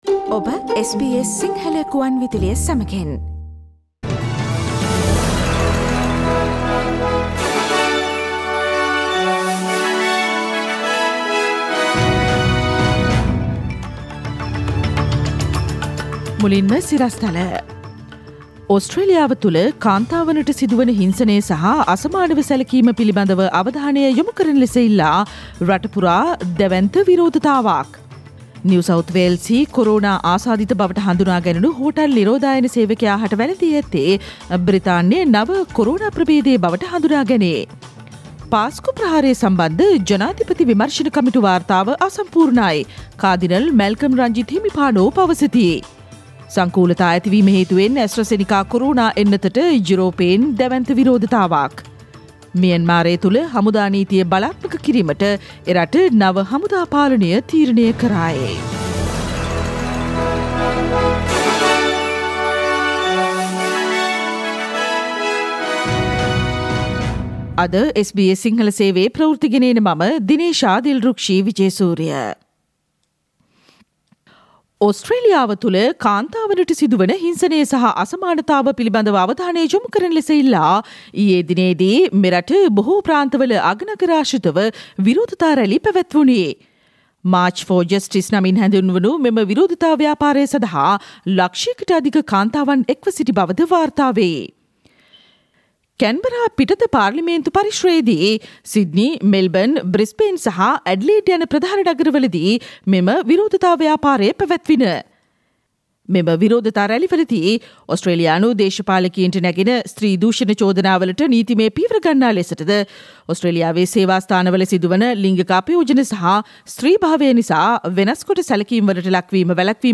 Here are the most prominent news highlights from SBS Sinhala radio daily news bulletin on Tuesday 16 March 2021.